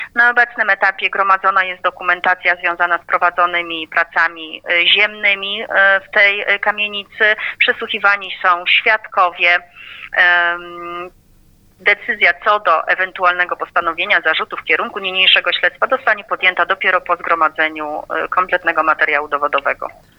O śledztwie mówi Małgorzata Witkowska, pełniąca obowiązki prokuratora rejonowego Radom Wschód: